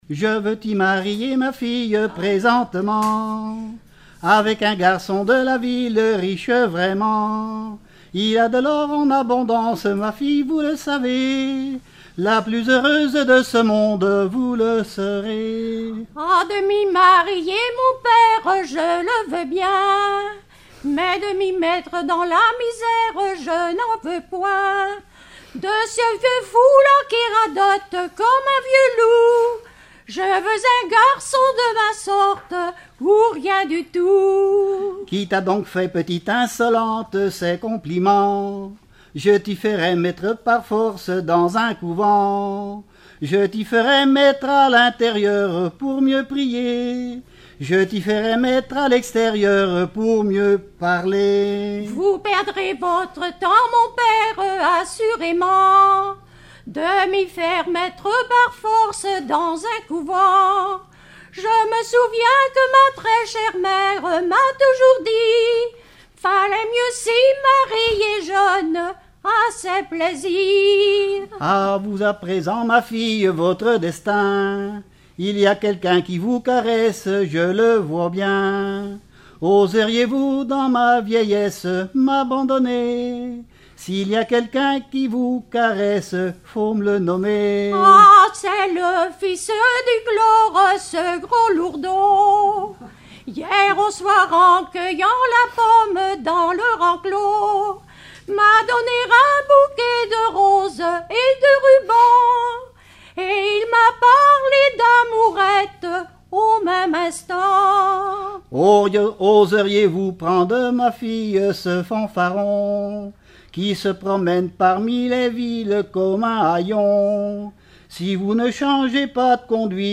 Genre dialogue
Répertoire de chansons populaires et traditionnelles
Pièce musicale inédite